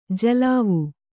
♪ jalavu